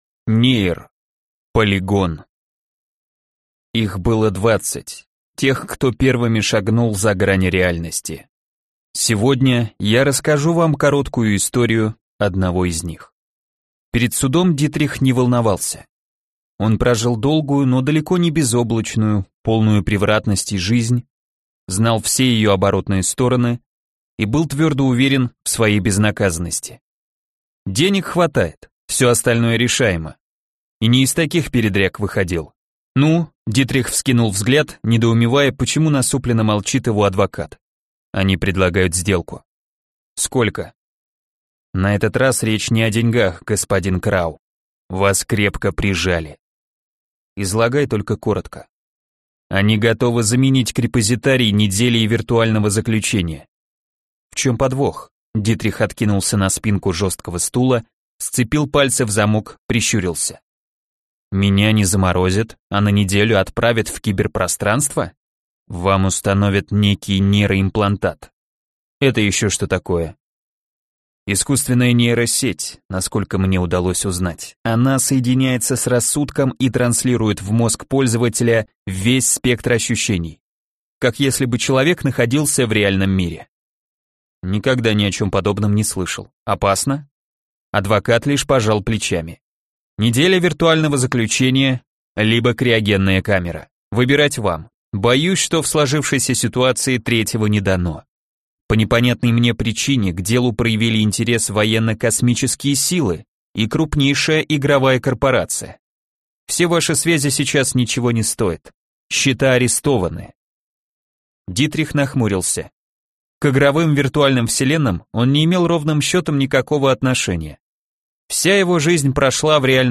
Aудиокнига Полигон